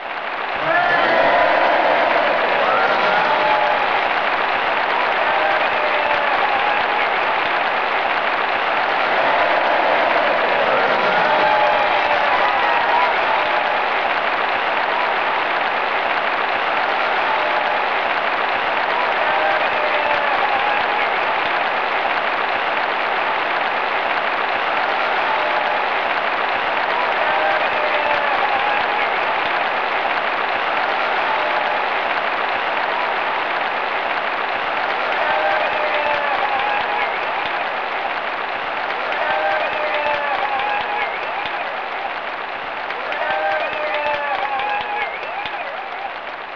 General_Cheer2.wav